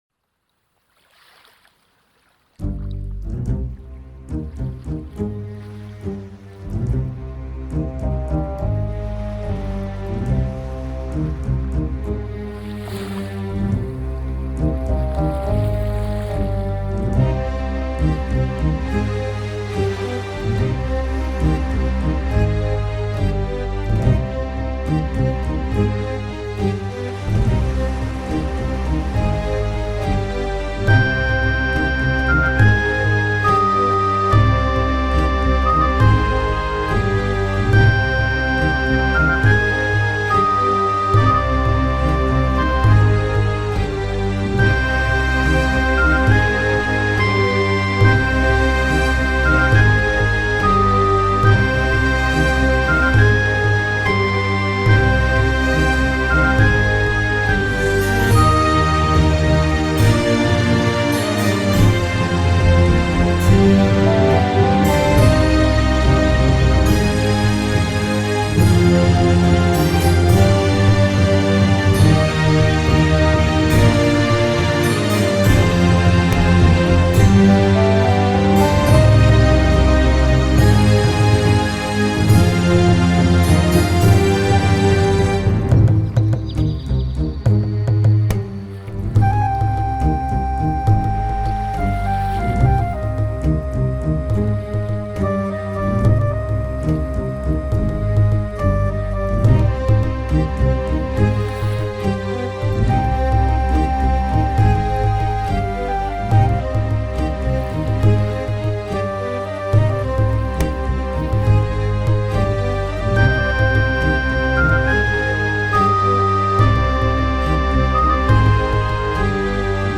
main melody is even quite lyrical. The key is C minor.
accompanied by rumble of waves